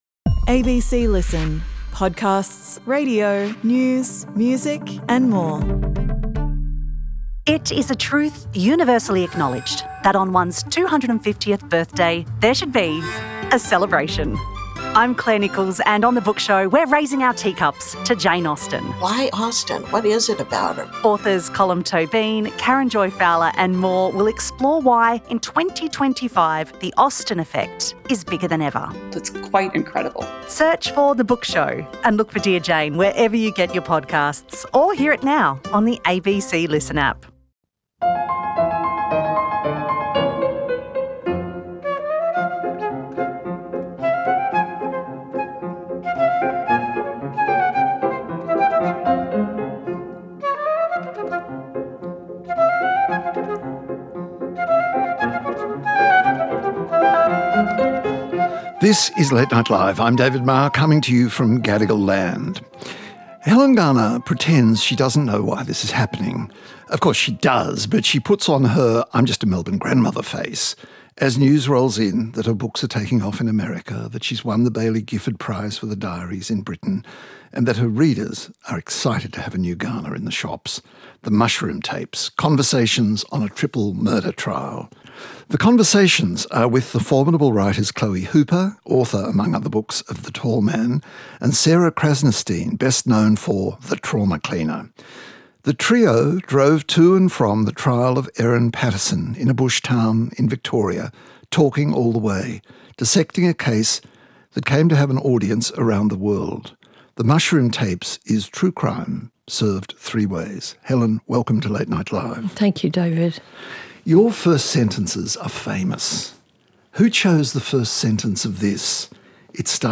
… continue reading 2012 حلقات # Society # Politics # Australia # News # US Politics # News Talk # ABC Radio